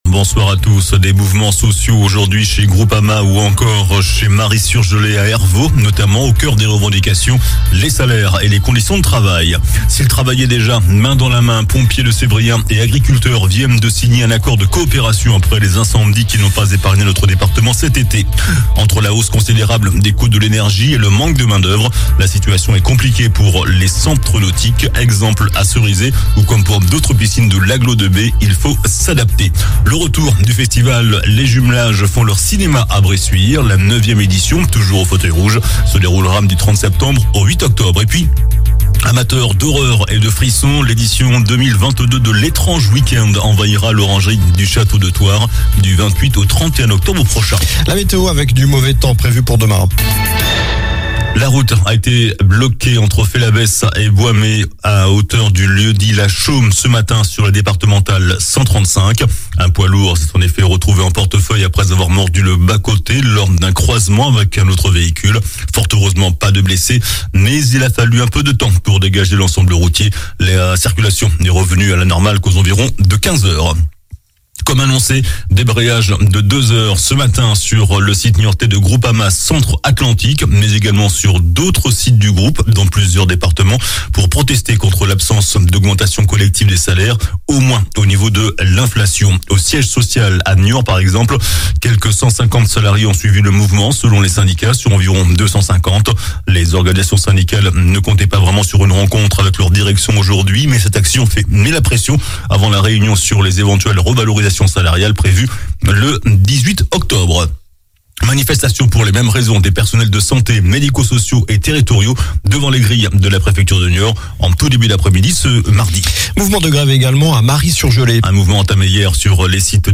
JOURNAL DU MARDI 27 SEPTEMRE ( SOIR )